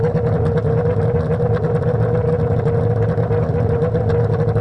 rr3-assets/files/.depot/audio/Vehicles/v8_01/v8_01_idle2.wav
v8_01_idle2.wav